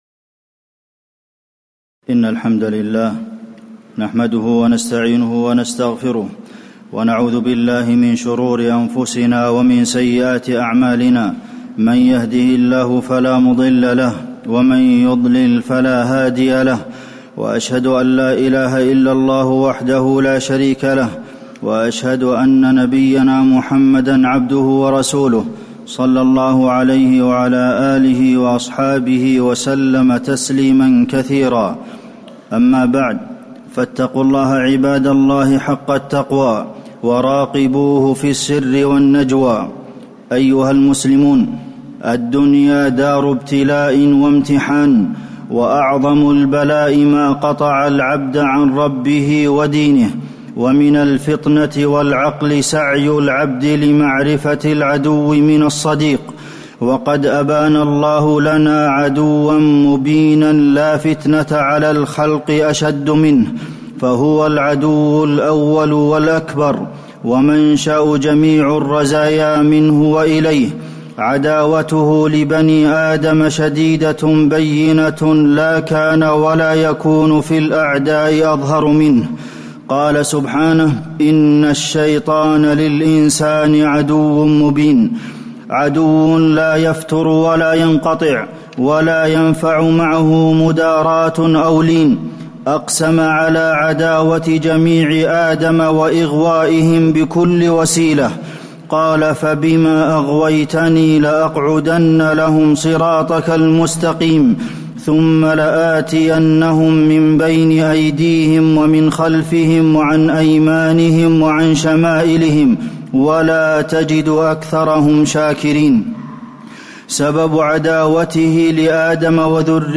تاريخ النشر ٢٧ رجب ١٤٣٩ هـ المكان: المسجد النبوي الشيخ: فضيلة الشيخ د. عبدالمحسن بن محمد القاسم فضيلة الشيخ د. عبدالمحسن بن محمد القاسم عداوة الشيطان لبني آدم